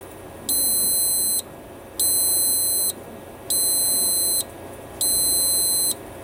【ネットワークレコーダ】ビープ音が発生する条件について
ビープ音とは：各種イベントの条件が満たされた場合やレコーダに問題が発生した場合に発生する音